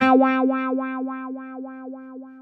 Guitar - BRIO.wav